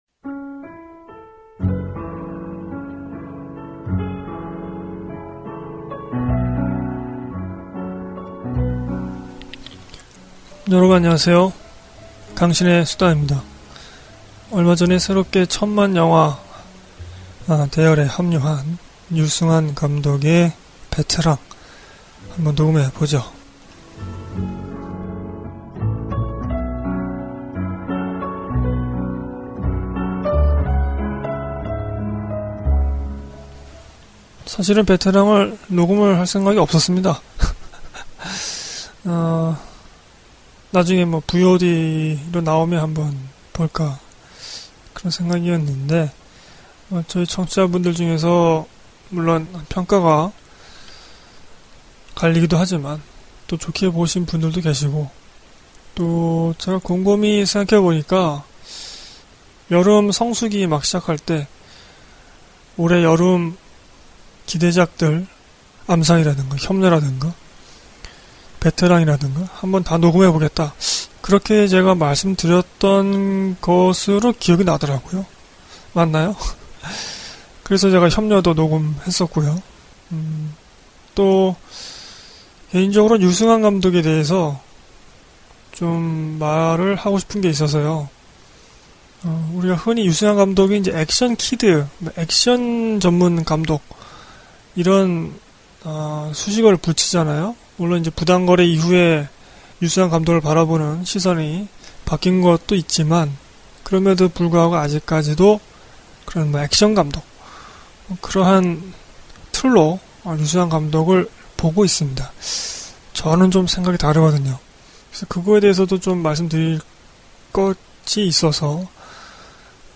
* 밤 늦게 녹음하느라 음색이나 발음 등이 상쾌하지 않네요;; 좀 시큰둥한 것으로 들리던데..